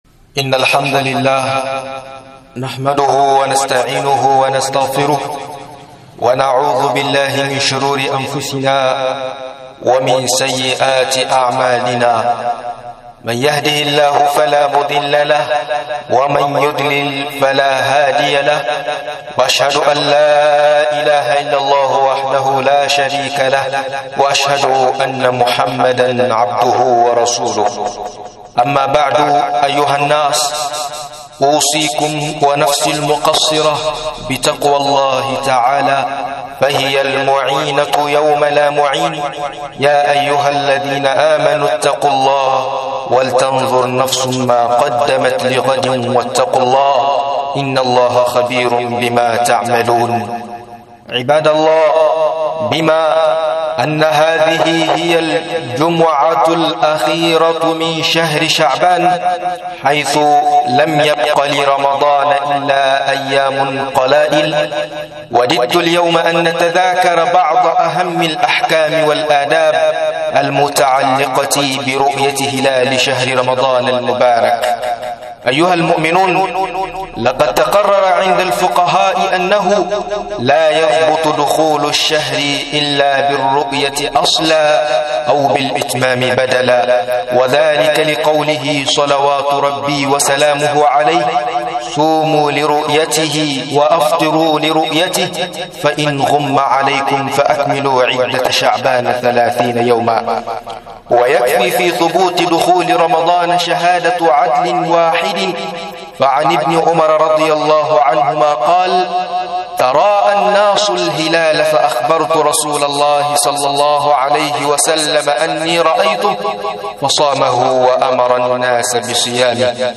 Ganin Ramadan (ladubba da hukunce hukunce) - MUHADARA